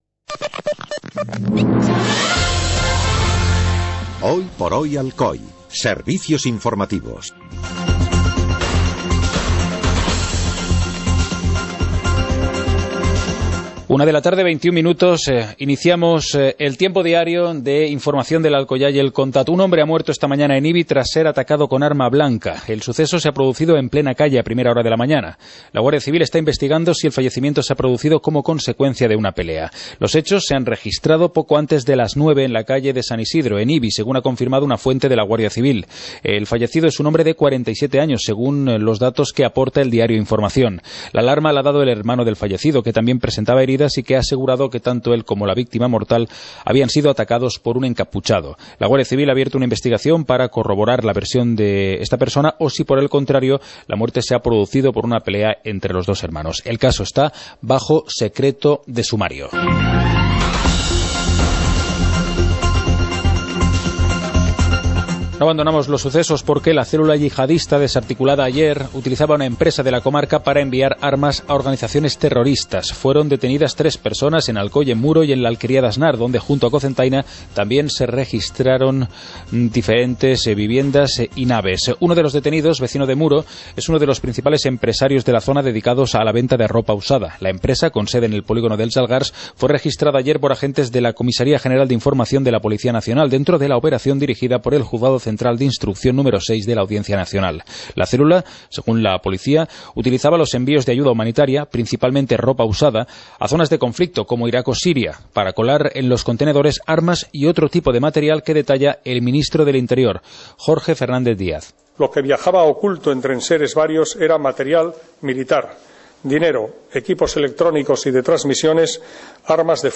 Informativo comarcal - lunes, 08 de febrero de 2016